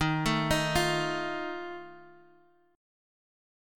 D#sus2b5 Chord